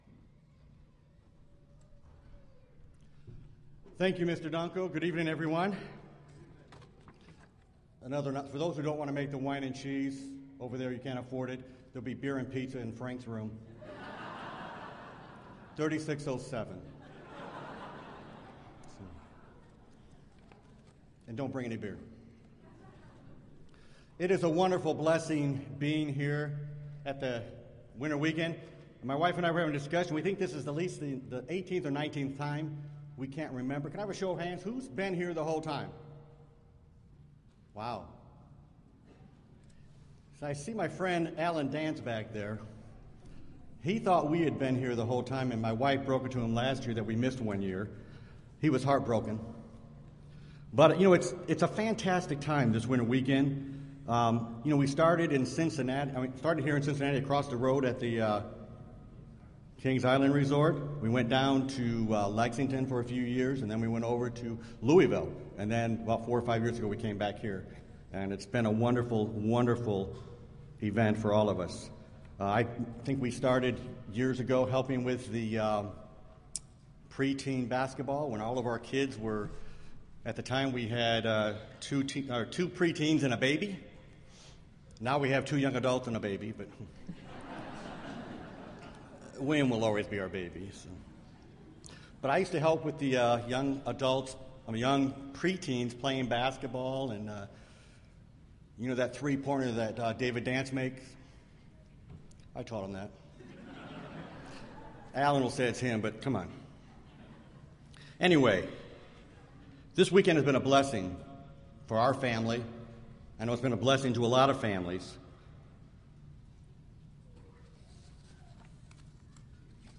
Listen to this important Bible study which addresses many issues facing our youth today, as they work hard to follow God in a challenging world. This Bible study was given during the 2015 Winter Family Weekend in Cincinnati, Ohio.